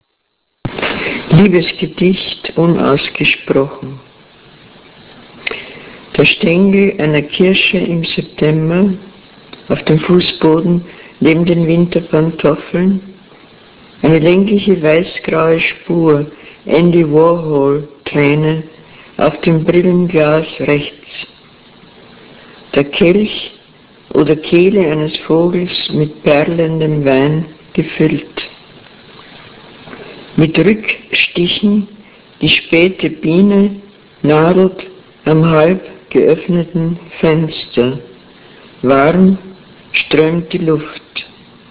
Friederike Mayröcker O-Ton